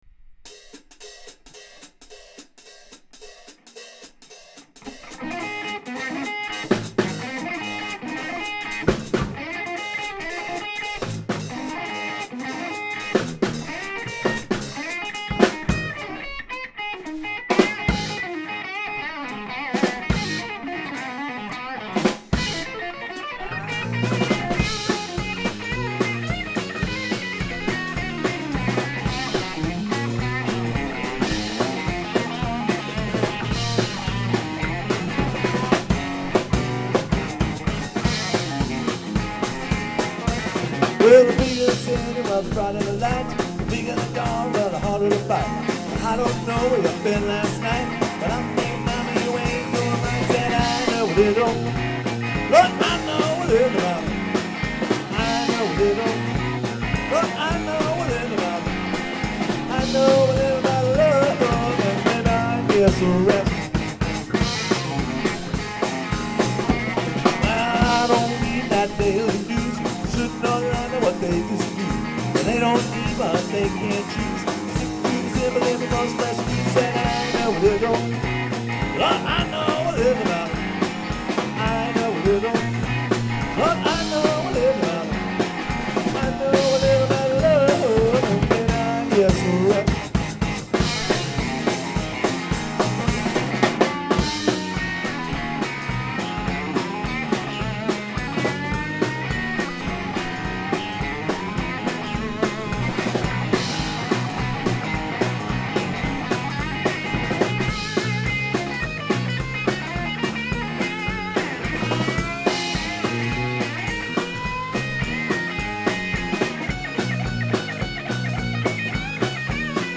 Live sound bytes: Foo Fighters